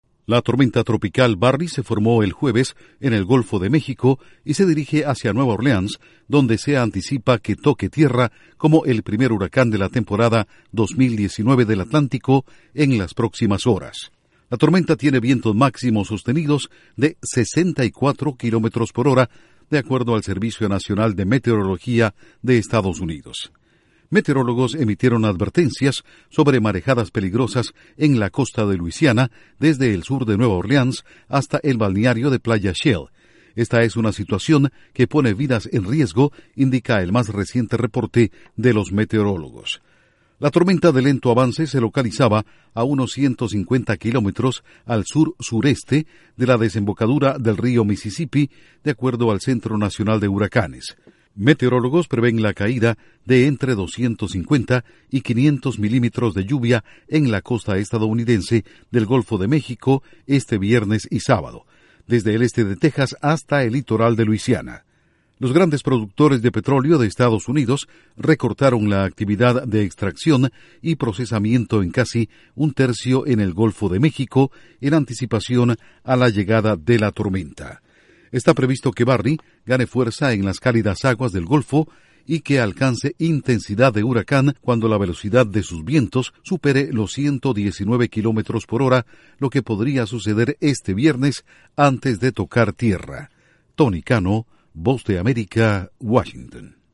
Tormenta Barry en dirección a Luisiana golpea plataformas de crudo en Golfo de México. Informa desde la Voz de América en Washington